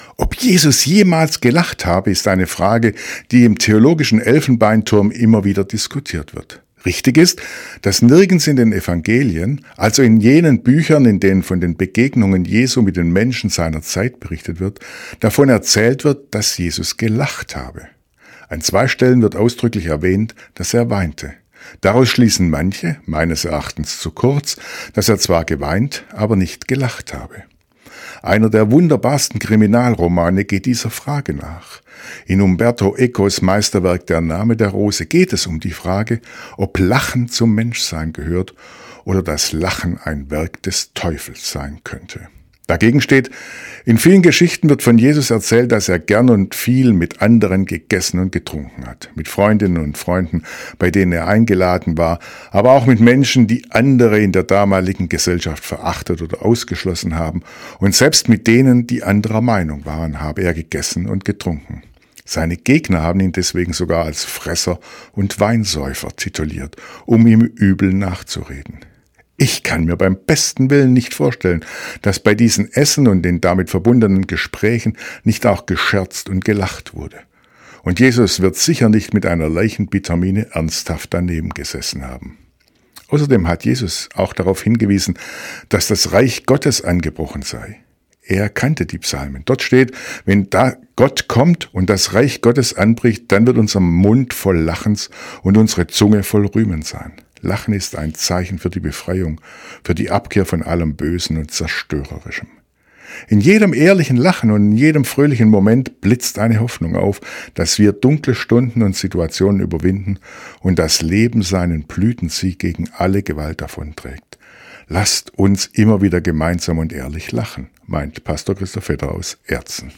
Weserbergland: Radioandacht vom 11. März 2026 – radio aktiv